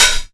Index of /90_sSampleCDs/USB Soundscan vol.20 - Fresh Disco House I [AKAI] 1CD/Partition D/01-HH OPEN